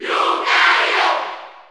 Category: Crowd cheers (SSBU) You cannot overwrite this file.
Lucario_Cheer_Spanish_NTSC_SSB4_SSBU.ogg